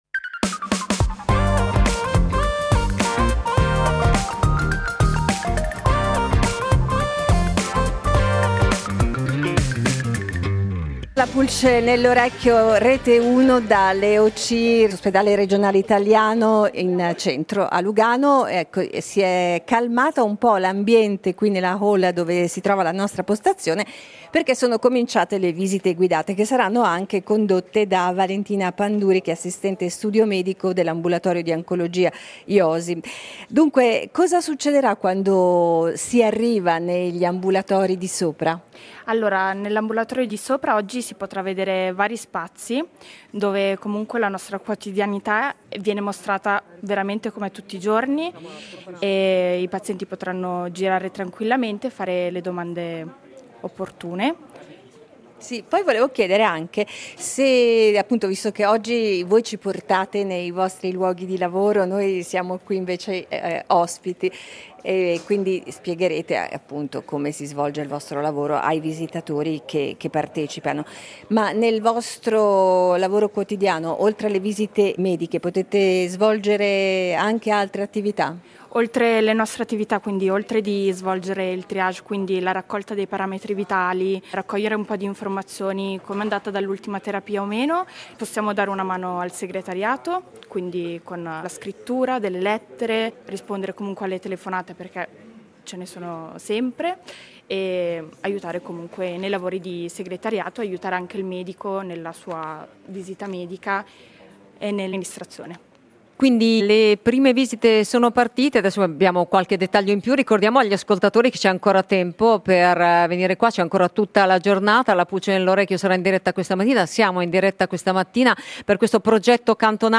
In diretta dall’Ospedale Italiano di Lugano